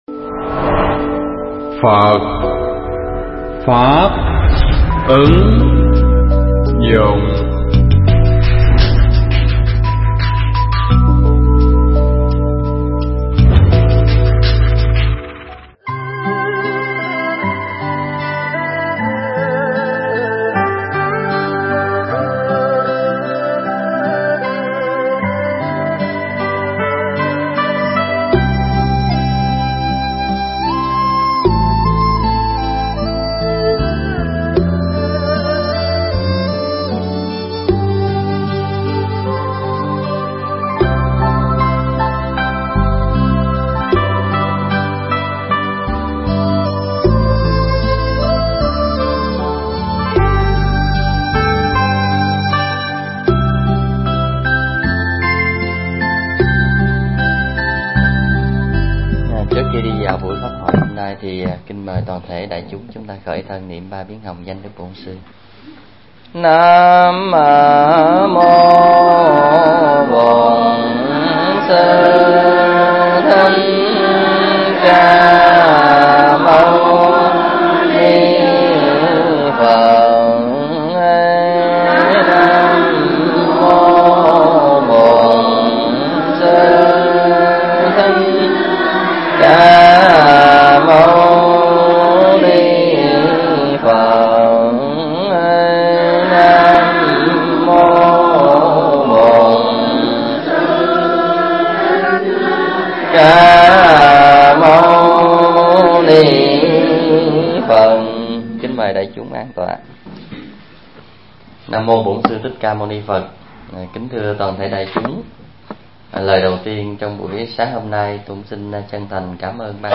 Pháp thoại Đạo Phật Có Mê Tín Không
tại Đạo Tràng Đức Vân (Pháp Quốc)